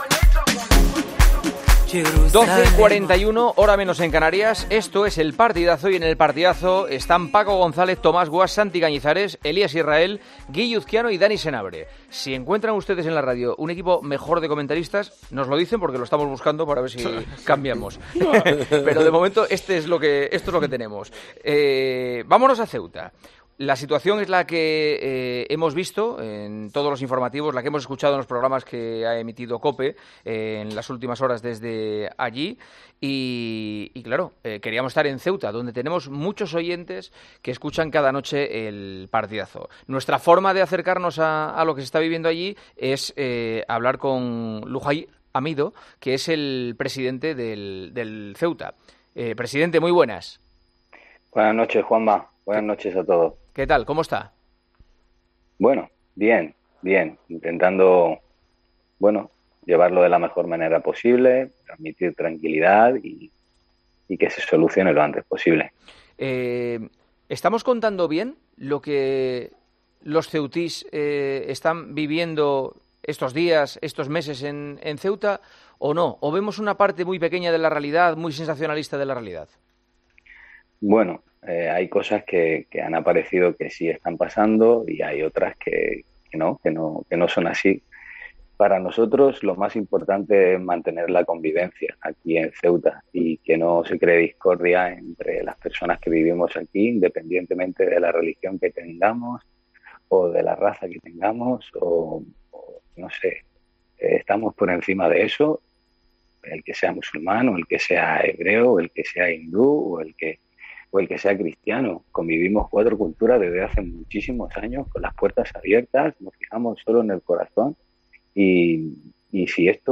ha pasado este miércoles por los micrófonos de El Partidazo de COPE para contarnos cómo se está viviendo la crisis migratoria en la ciudad.